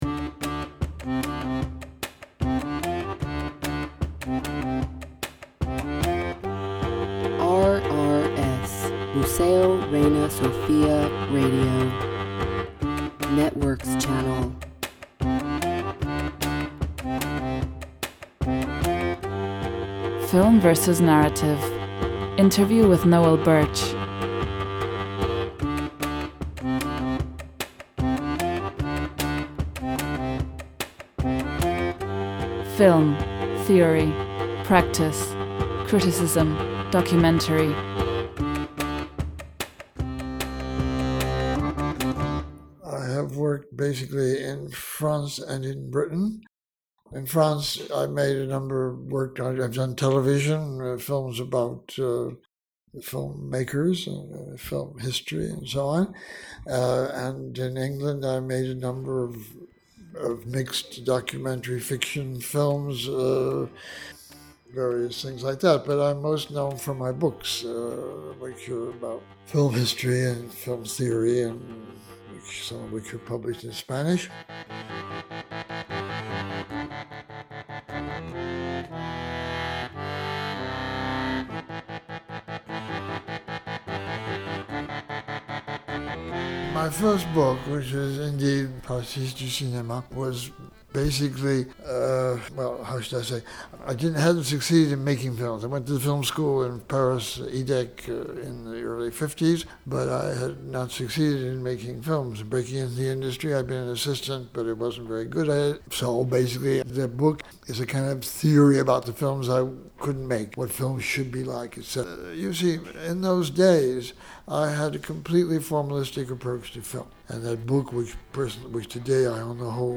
Interview with Noël Burch .